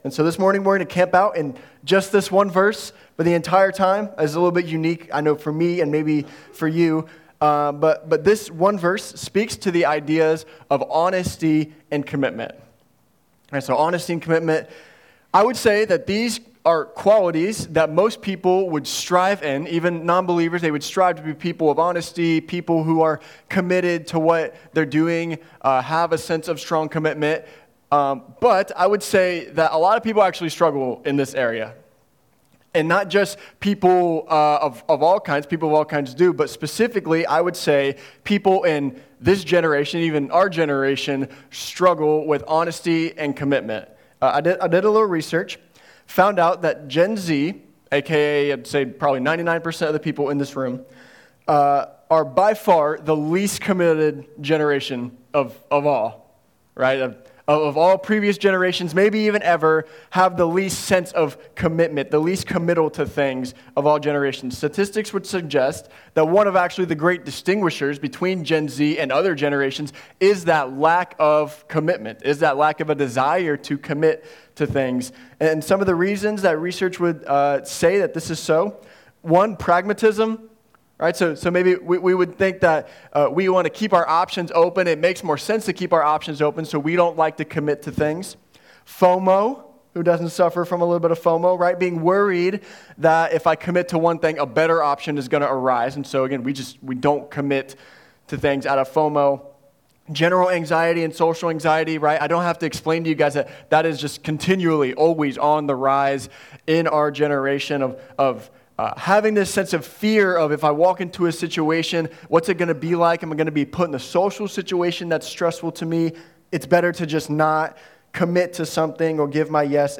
A sermon series on the book of James in the New Testament. This sermon is on James 5:12.